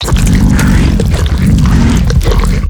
vampire_sucking.ogg